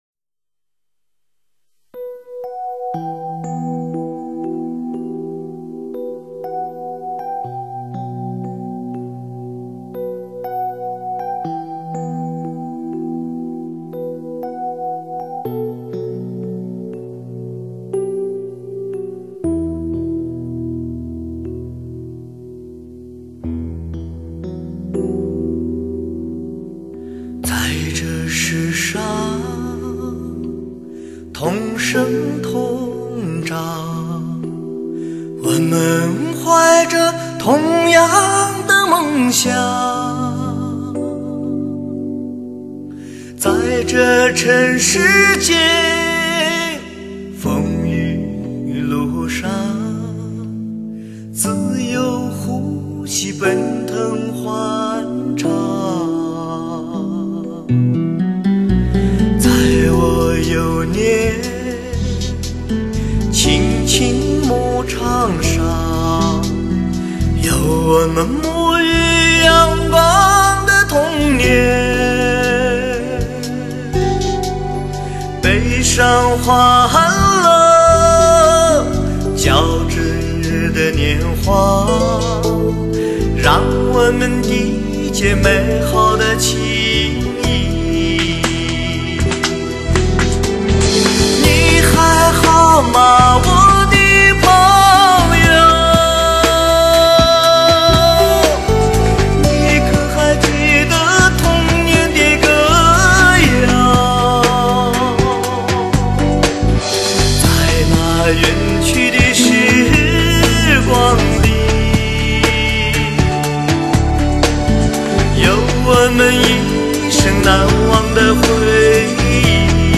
整张唱片中没有太多华丽的音符和浮躁的情绪。